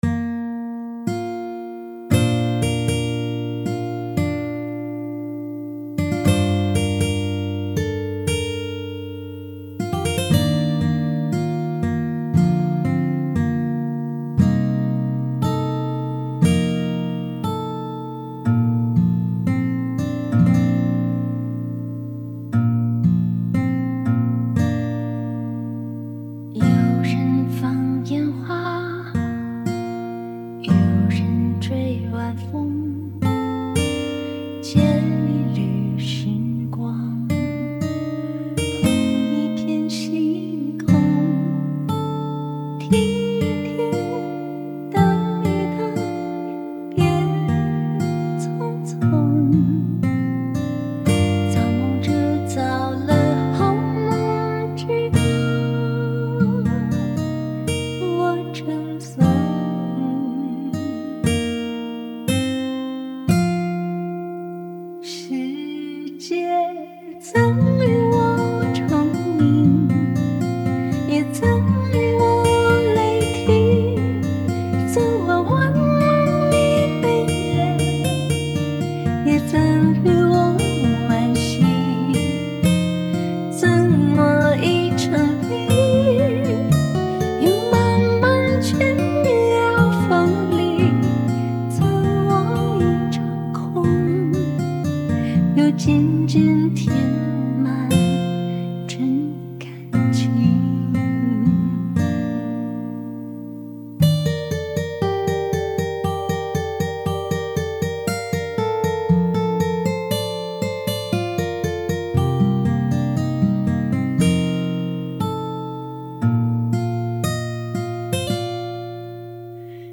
谱内音轨：吉他 旋律 和弦 歌词
曲谱类型：弹唱谱